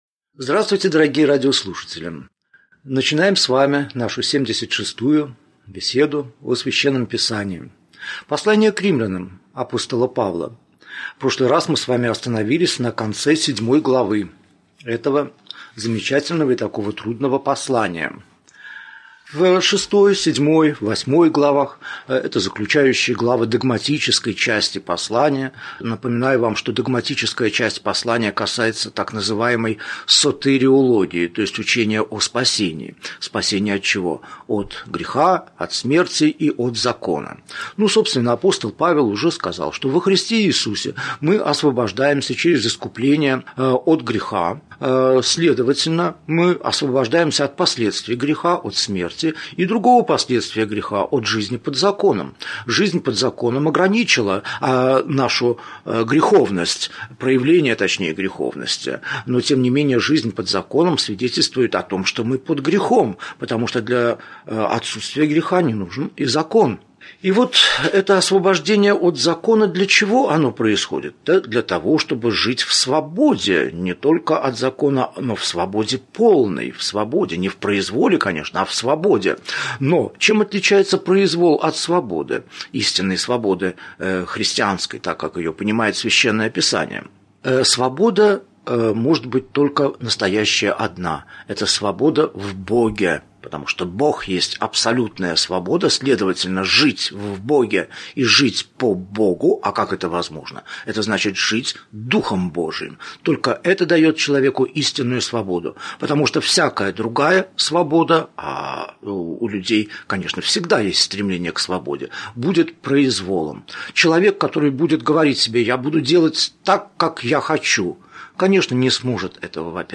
Аудиокнига Беседа 76. Послание к Римлянам. Глава 8, стих 1 – 17 | Библиотека аудиокниг